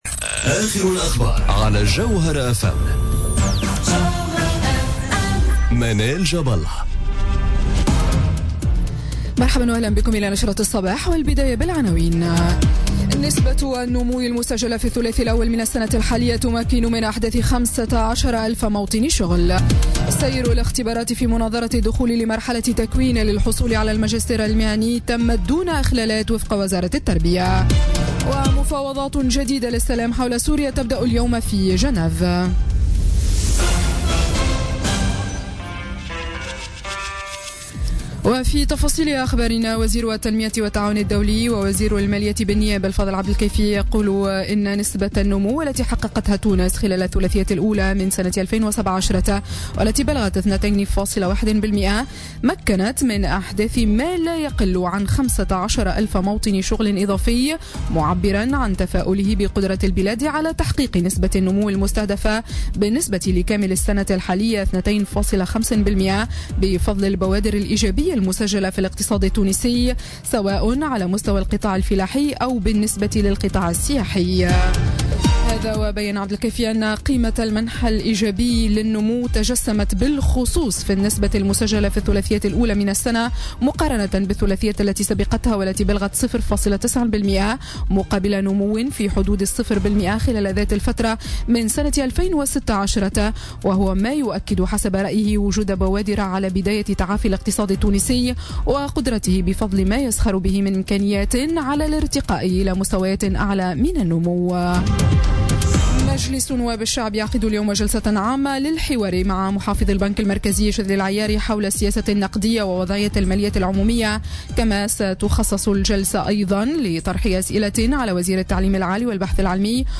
نشرة أخبار السابعة صباحا ليوم الثلاثاء 16 ماي 2017